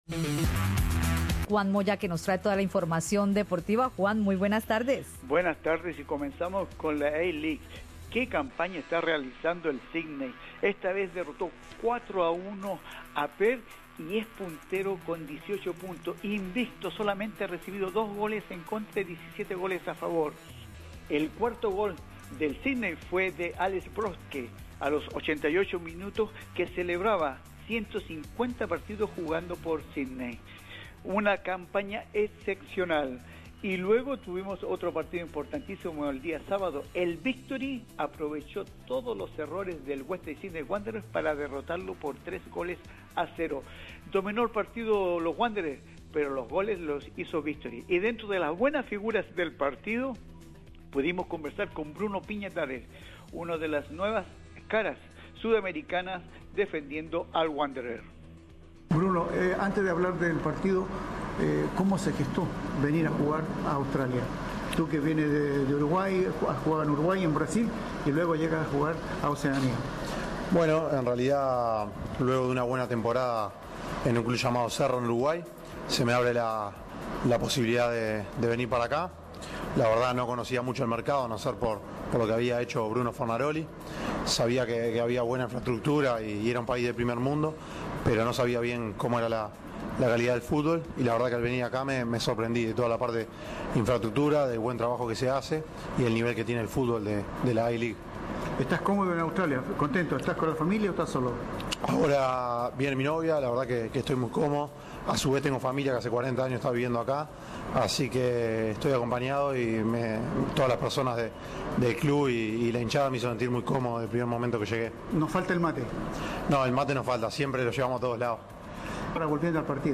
De Uruguay a Oceanía: Entrevista